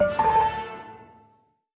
Media:Resort_Royale_King_001.wav 部署音效 dep 局内选择该超级单位的音效